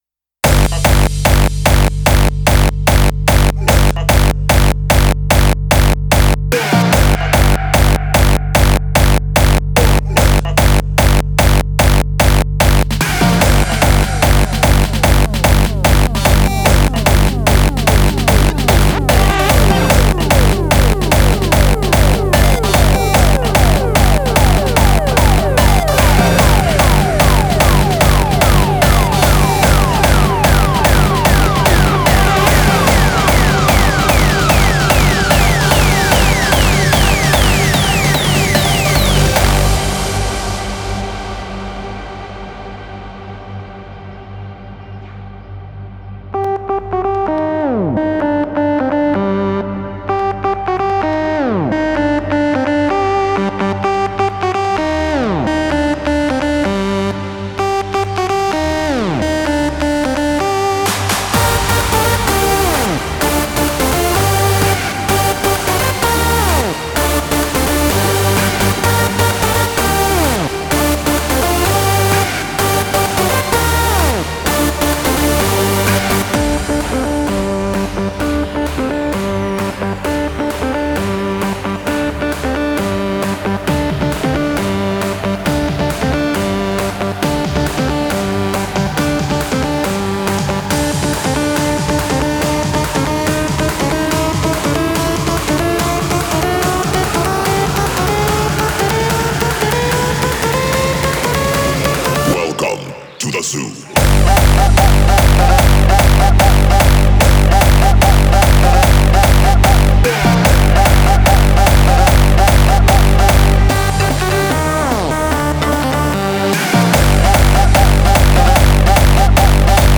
• Категория:Hardstyle